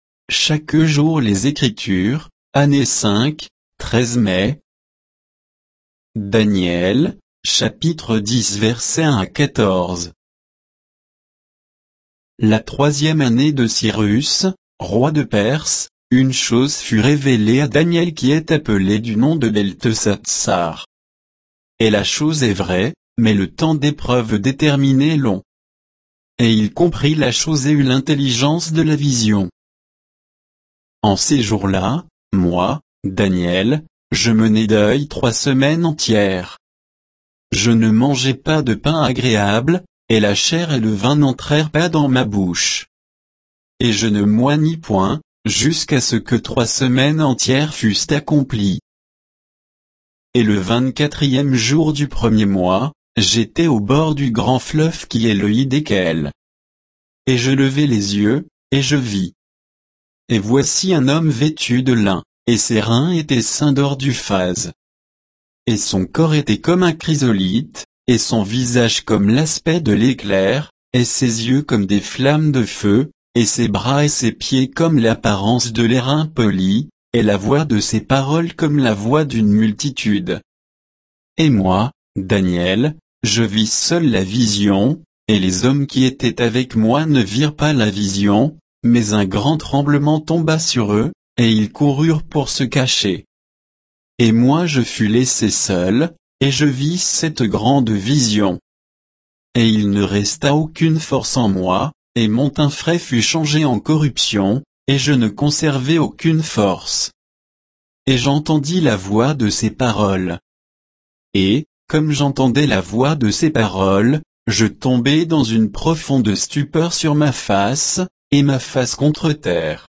Méditation quoditienne de Chaque jour les Écritures sur Daniel 10